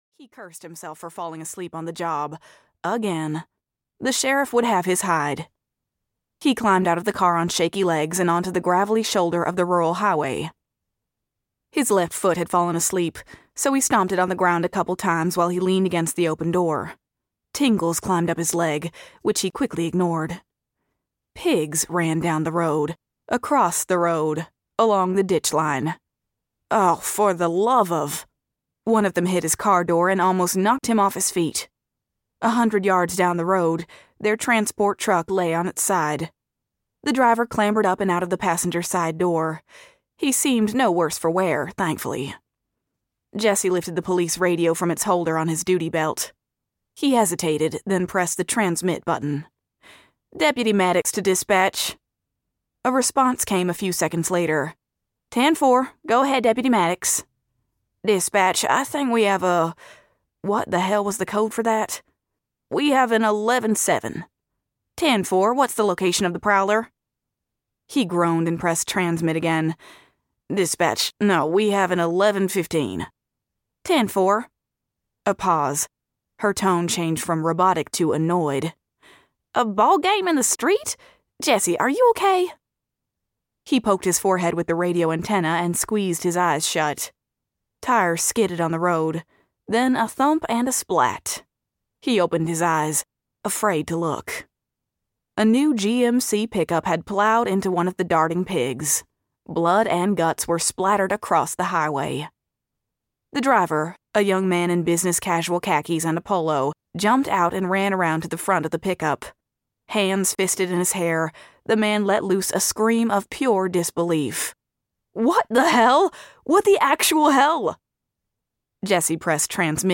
Jesse's List (EN) audiokniha
Ukázka z knihy